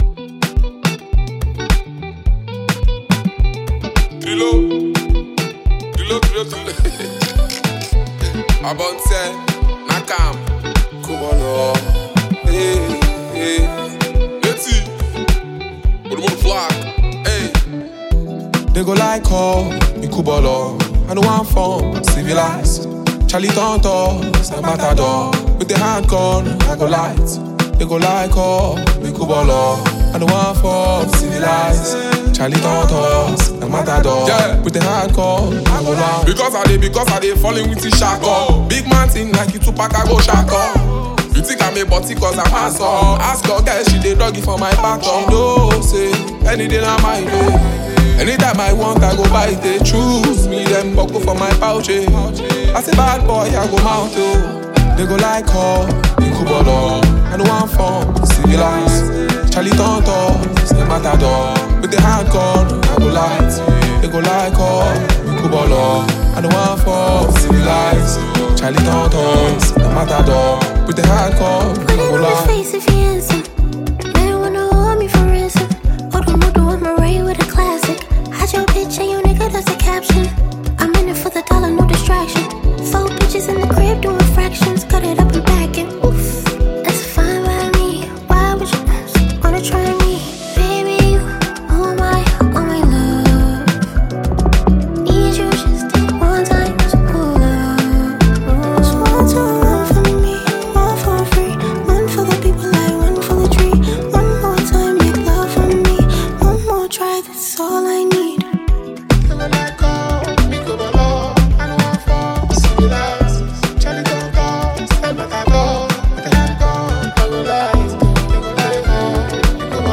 Phenomenon talented Nigerian rap artist and performer